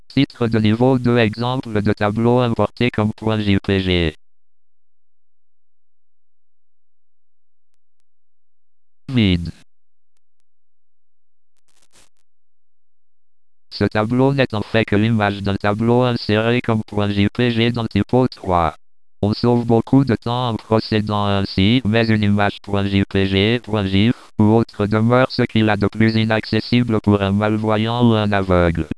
Sans titre ni texte de remplacement, le logiciel de lecture d'écran ne peut pas lire le contenu d'une image. Mettez-vous à la place d'une personne non voyante en écoutant, les yeux fermés, comment le logiciel de lecture d'écran interprète l'image ci-dessus.